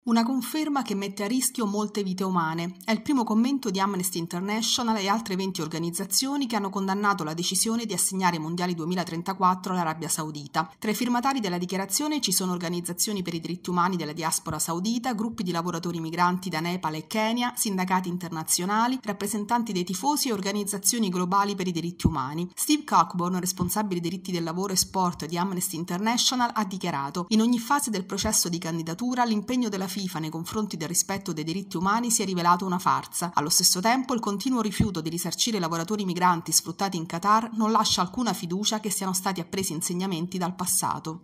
Domenica 23 marzo torna l’iniziativa promossa da esponenti del mondo della cultura, della società civile e del mondo del lavoro. Il servizio